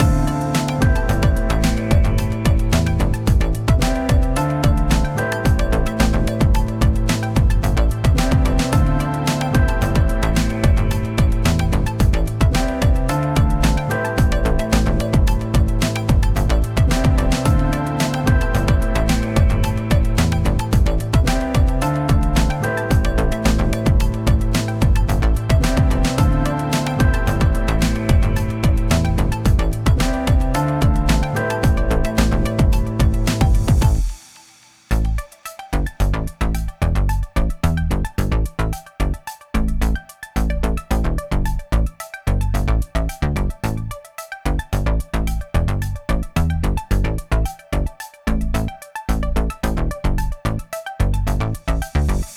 【ループ＆バリエーション＆耐久版あり】探偵や推理、謎解きの雰囲気があるBGMです
かっこいい/おしゃれ/ダーク/不気味/サスペンス/ゲーム/アニメ
▼ループ版